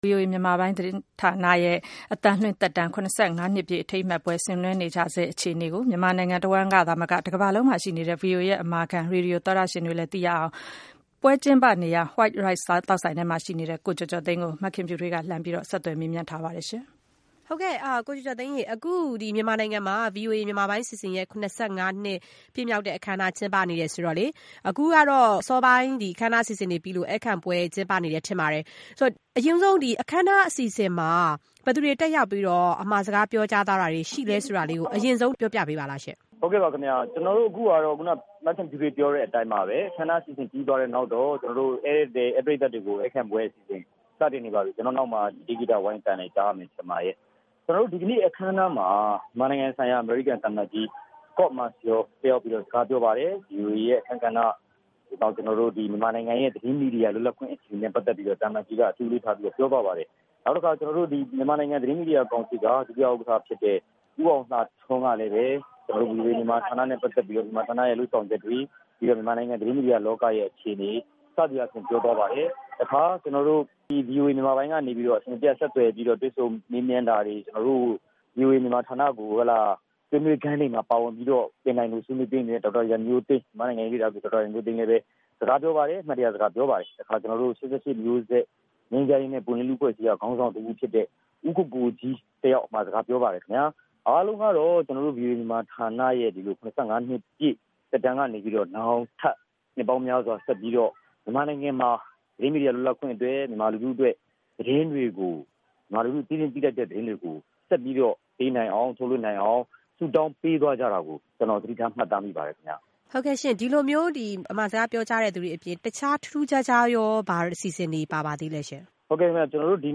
VOA ၇၅ နှစ်ပြည့် နှစ်ပတ်လည်အခမ်းအနားမှာ ကန်သံအမတ်ကြီးမိန့်ခွန်းပြော